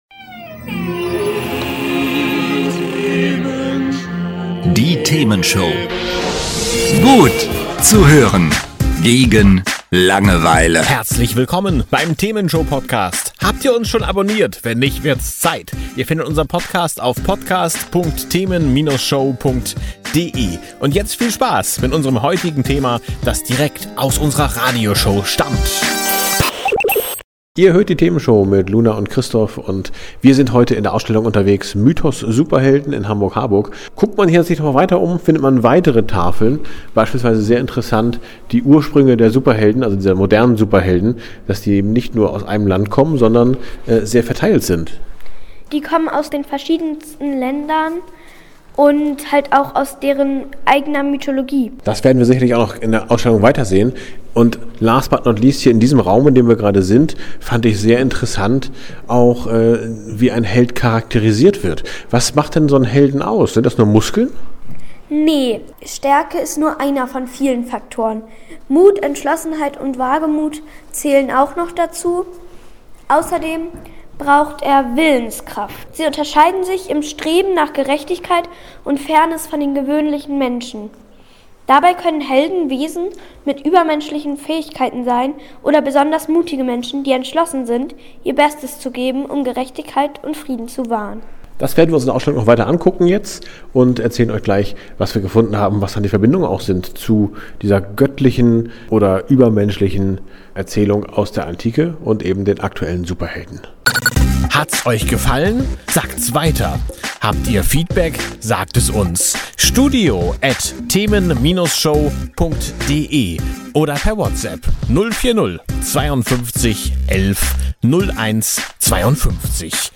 direkt in die Ausstellung „Mythos Superhelden“ nach Hamburg-Harburg. Wir gehen Fragen auf den Grund, die das herkömmliche Bild unserer Leinwandhelden ordentlich ins Wanken bringen: Wir blicken hinter die glänzenden Fassaden der Superkräfte: Erfahrt, was einen wahren Helden wirklich ausmacht – sind es nur die Muskeln, oder ist es die unerschütterliche Willenskraft?